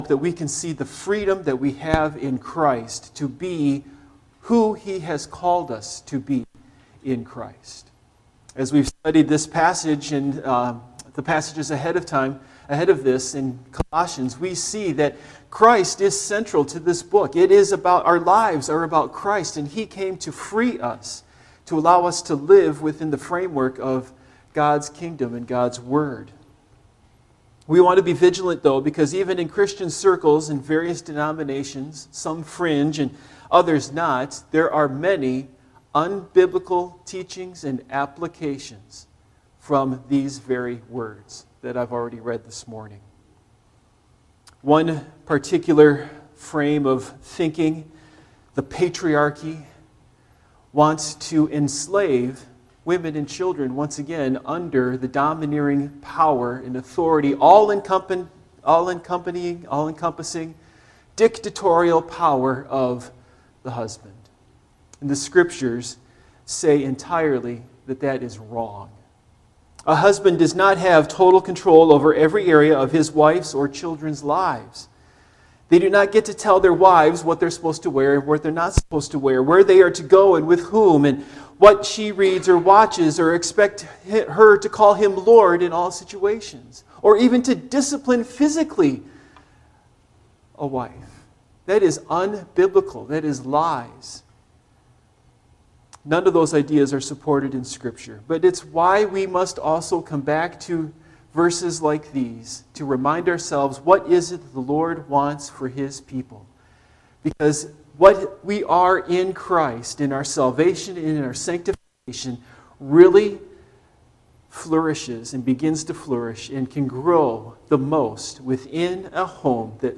Colossians Passage: Colossians 3:18-21 Service Type: Morning Worship Topics